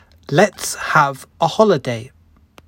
では、イギリス英語とアメリカ英語の発音を使い分けるために、以下の文章はアメリカ英語とイギリス英語で読み上げます。